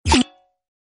PlayerJumpOnRocket.ogg